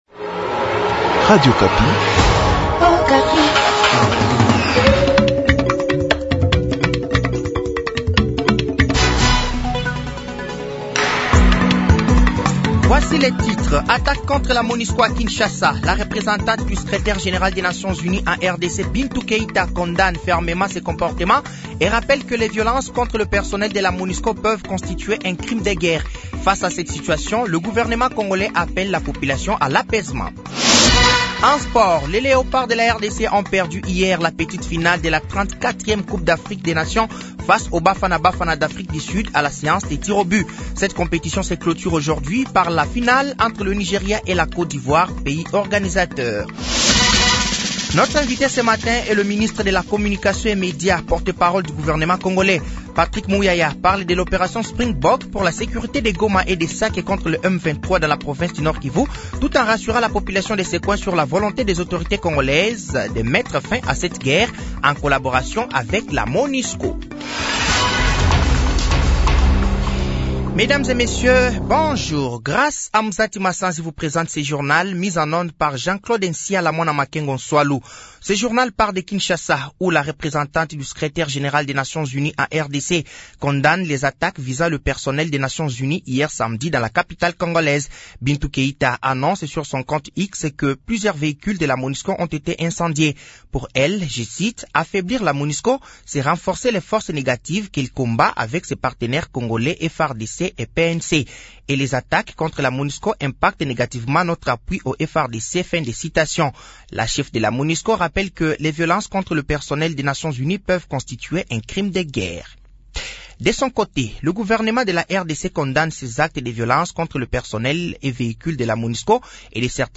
Journal français de 7h de ce dimanche 11 février 2024